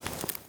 SFX_InvClose_01_Reverb.wav